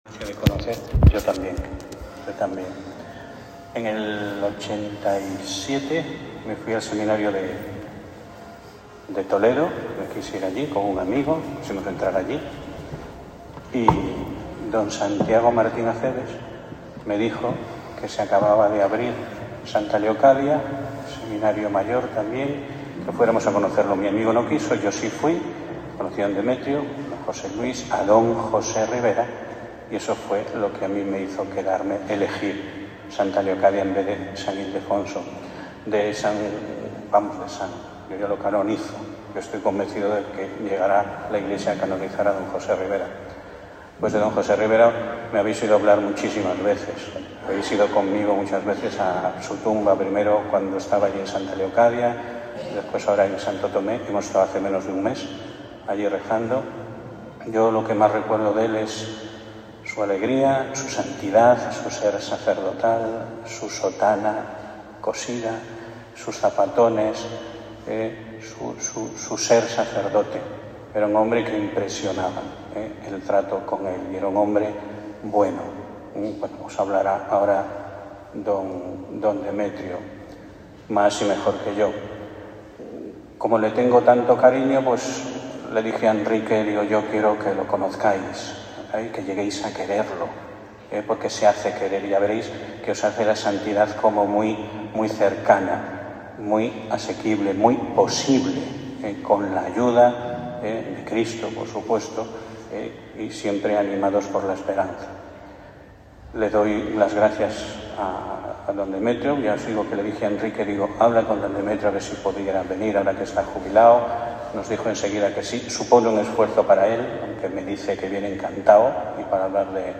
Venerable José Rivera - Vida y doctrina I Mons. Demetrio Fernández González, Obispo emérito de Córdoba Ejercicio de los Sábados Primera charla de Mons.